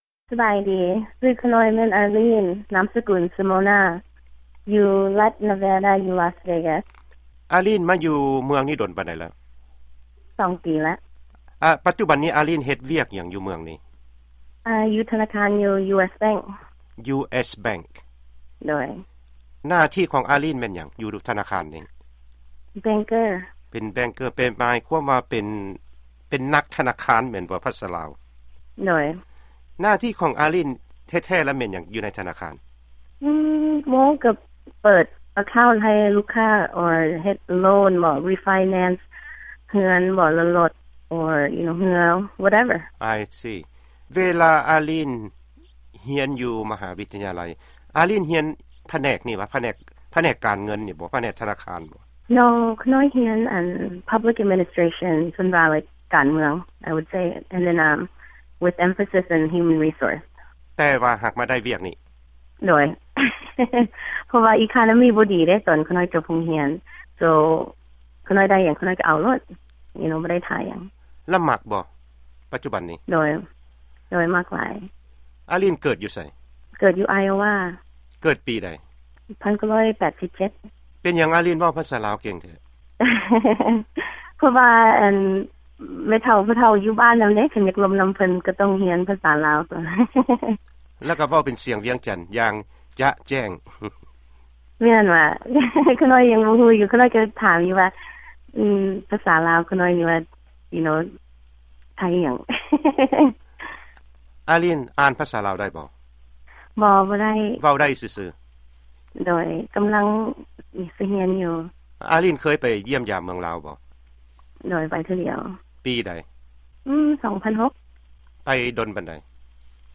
ວີໂອເອ-ລາວ ສຳພາດ